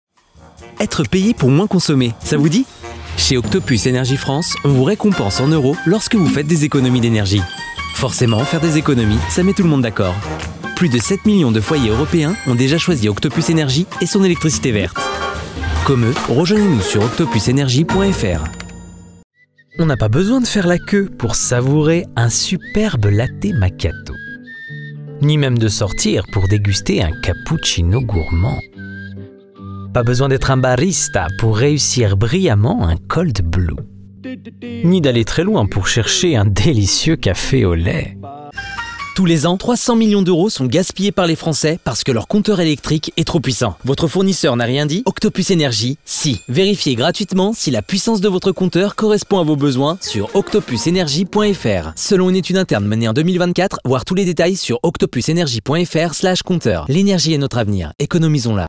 Jeune, Naturelle, Enjouée, Amicale
Commercial
He works from a private, acoustically treated studio equipped with professional gear, delivering broadcast-quality sound directly to clients or production studios.